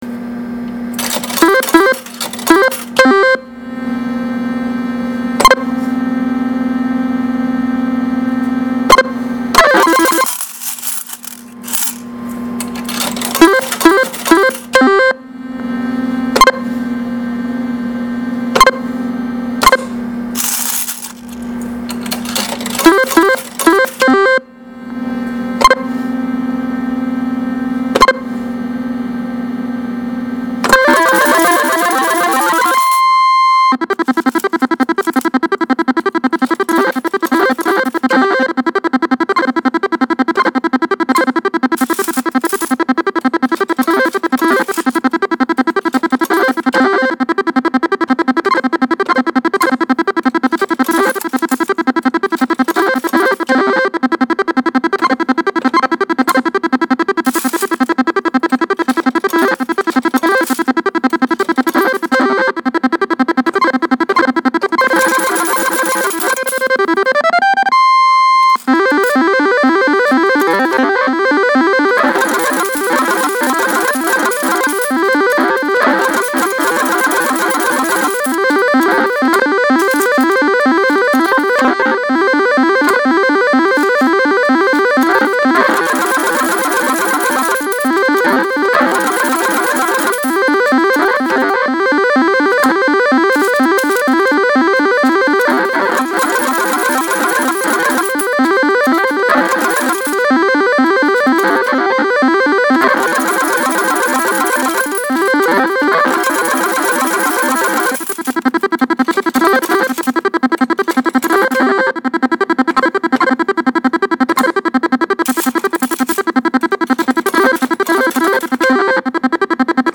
パチスロ実機
【 BIGボーナス音 】